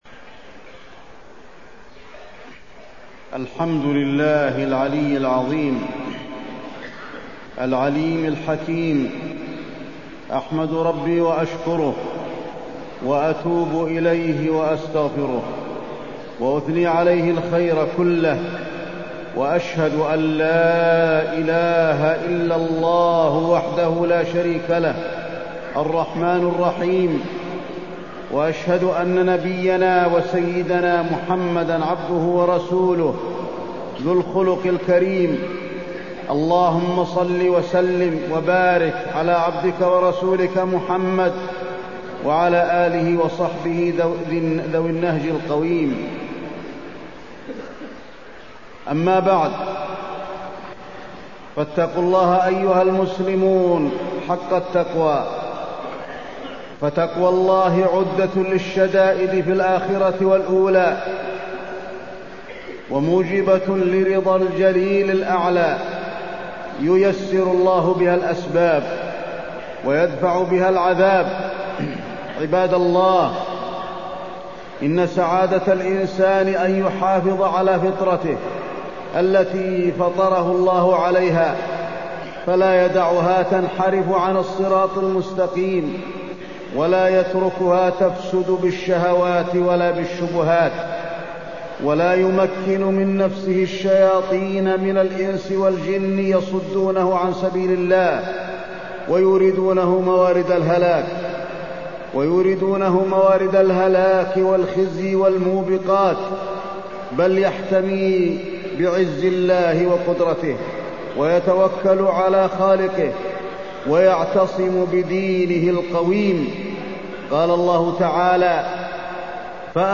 تاريخ النشر ٢٢ ذو الحجة ١٤٢٤ هـ المكان: المسجد النبوي الشيخ: فضيلة الشيخ د. علي بن عبدالرحمن الحذيفي فضيلة الشيخ د. علي بن عبدالرحمن الحذيفي الاستقامة